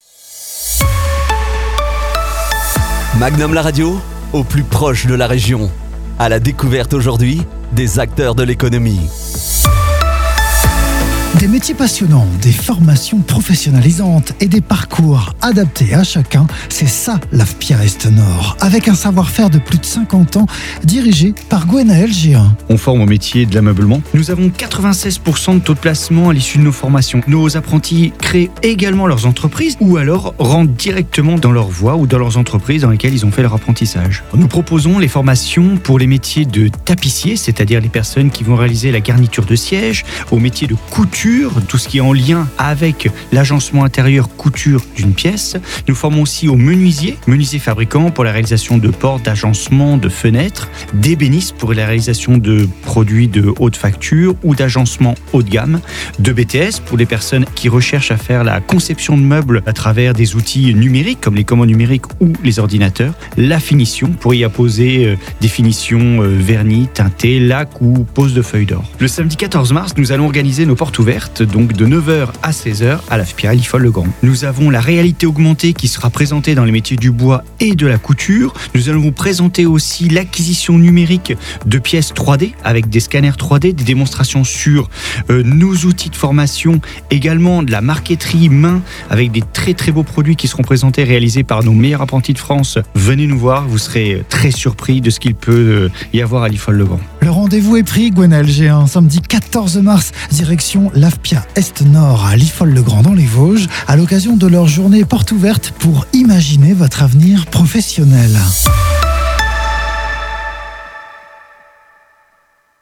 Publireportage